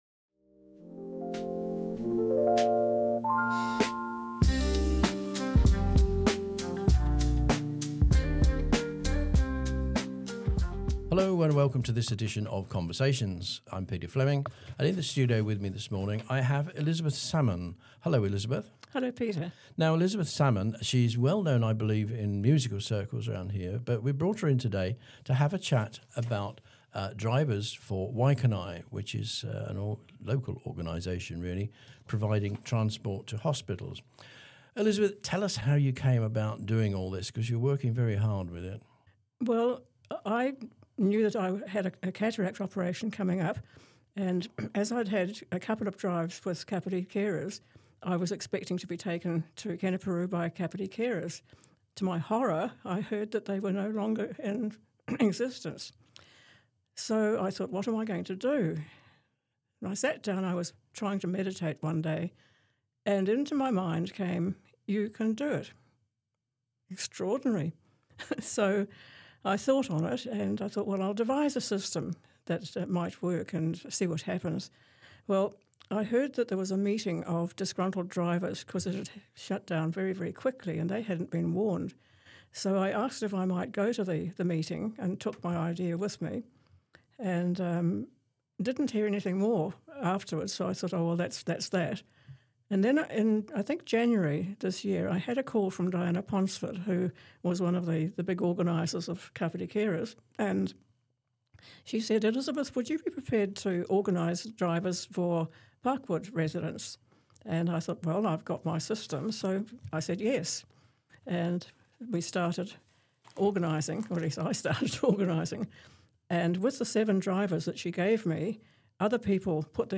A diverse range of conversations with interesting people from Kapiti, Horowhenua and across New Zealand.